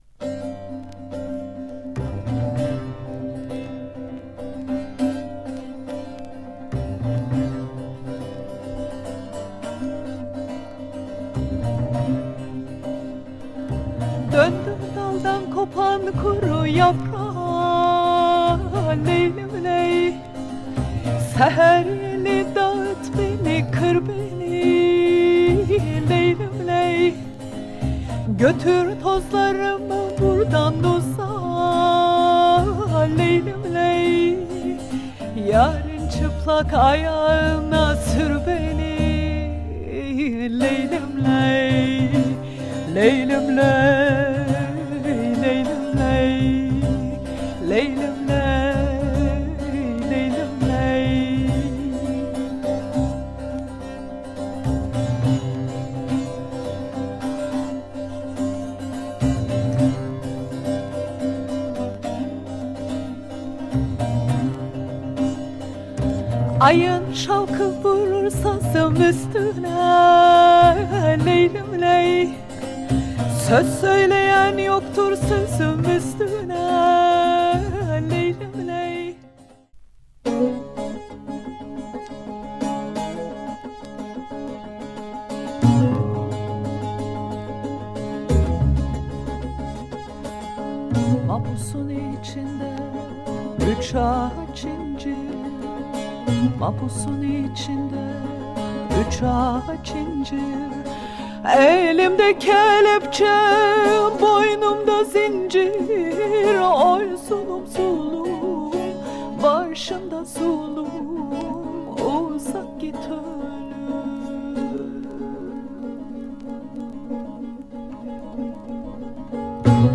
Superb oriental jazz album
bass